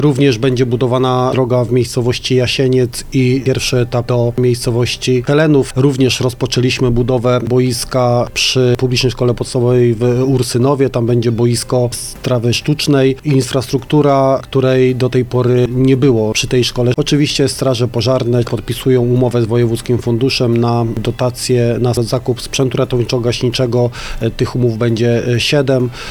To nie jedyna inwestycja, dodaje Hubert Czubaj i wymienia: